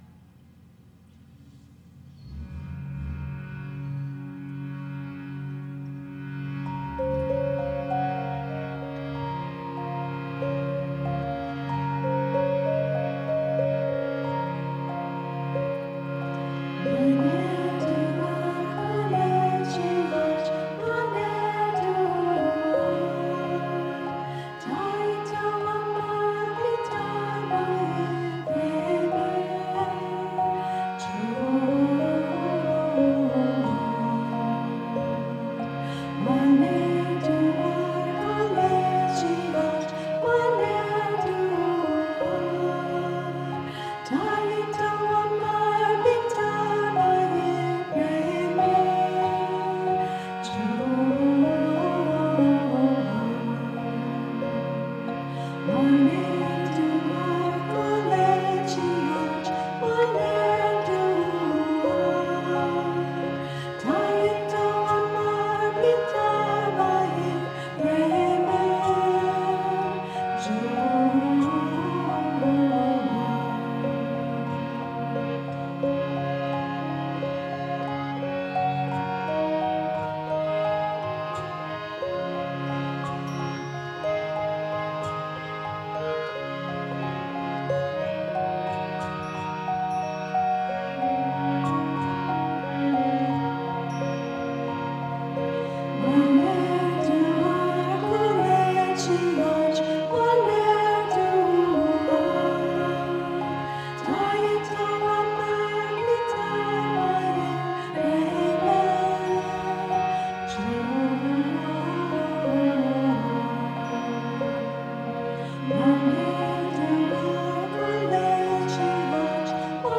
Musikalische Darbietungen von Sri Chinmoys Schülern, 13.
Live-Aufnahmen von Musikgruppen, die Lieder Sri Chinmoys während der Feierlichkeiten in New York aus Anlass von Sri Chinmoys Ankunft im Westen aufführen.